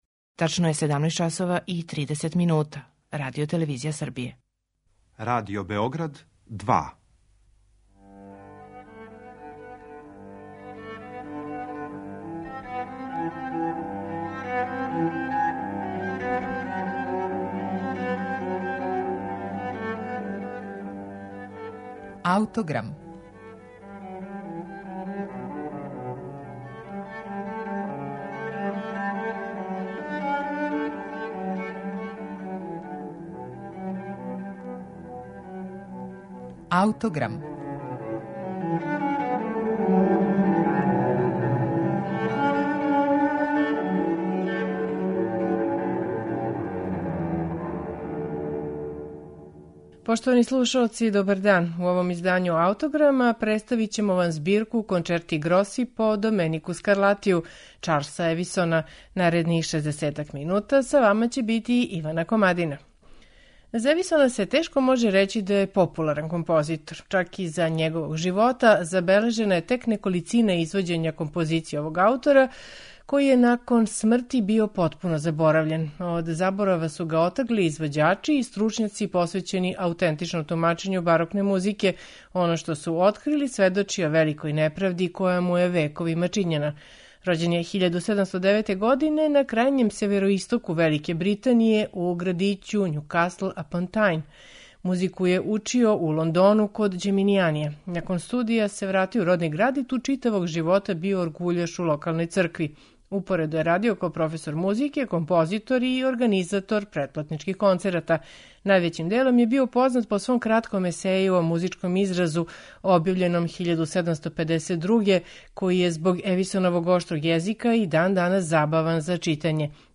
Скарлатијеве композиције које су оригинално биле намењене чембалу, маштовито је аранжирао за гудачки оркестар и објединио их у збирку од 12 кончерта гроса, створивши музику кoја је у подједнакој мери његова колико и Скарлатијева.